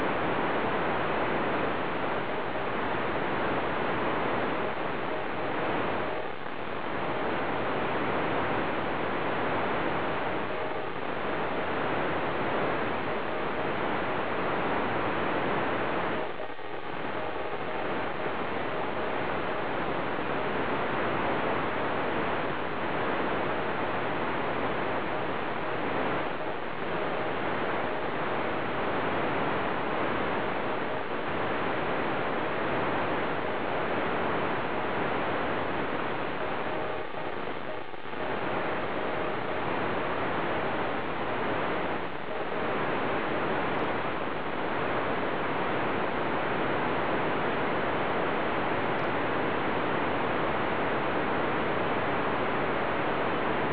受信設備：　 60cmパラボラアンテナ　アンテナ直下コンバータ
2007年７月３１日(火)　朝　強力に入感　F3でもIDを聴き取ることができました。
FMで受信したＩD